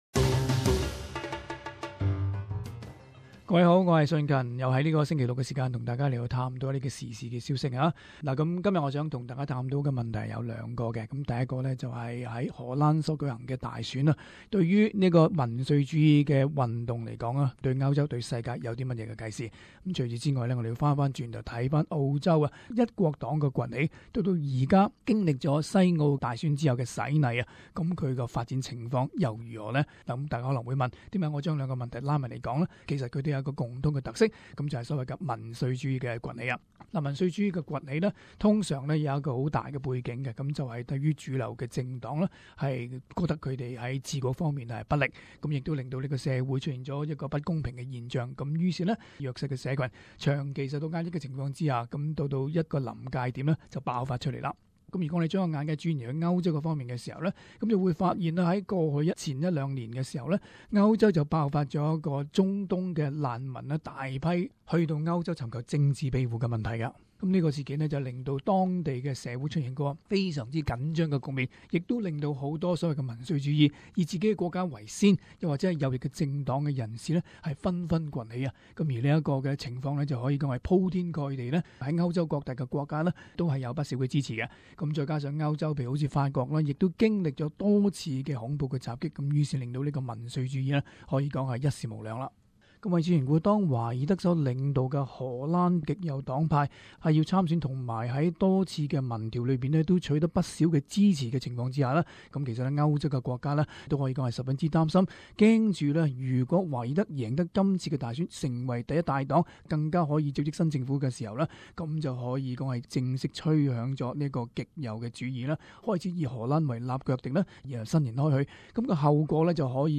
【時事報導】世界民粹主義的發展與一國黨的政治前景